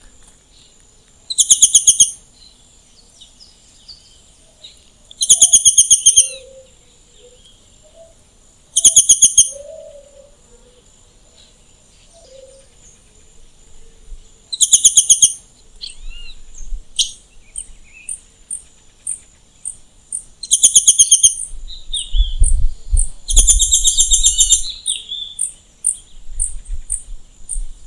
Ferruginous Pygmy Owl (Glaucidium brasilianum)
Detailed location: Riacho dos Remédios
Condition: Wild
Certainty: Observed, Recorded vocal